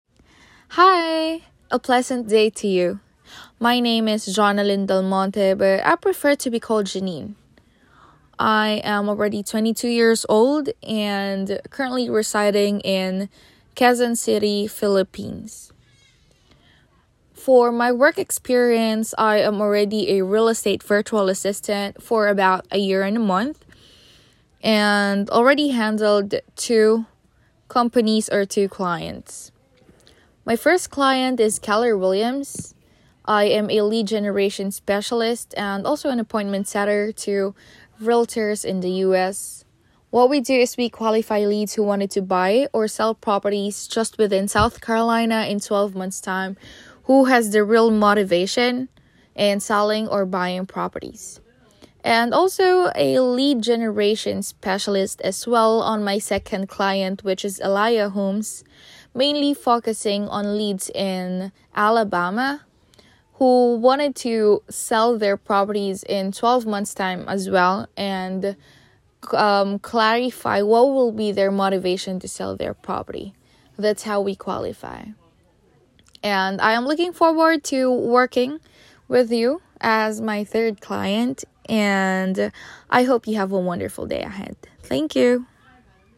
Self Introduction